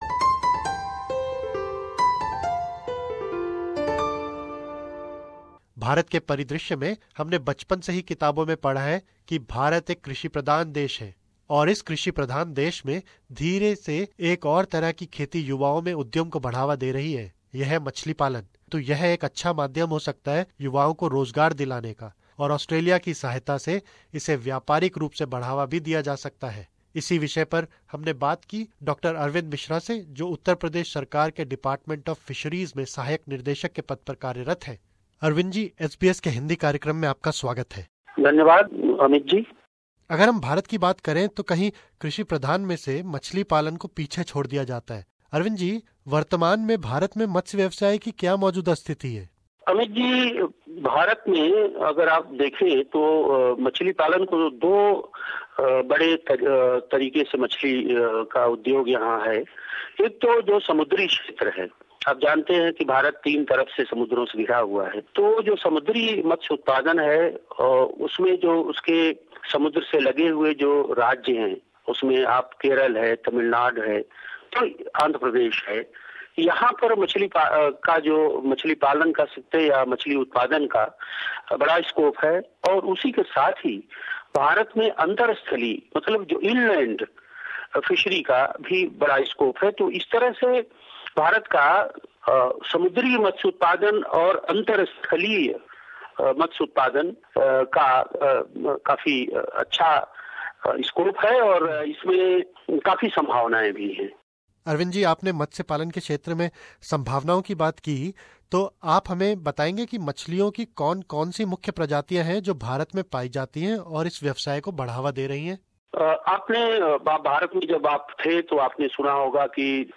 We talk to Dr Arvind Mishra, Director of Fisheries, Uttar Pradesh Government (India) about the possibilities in aquaculture trade between Australia and India.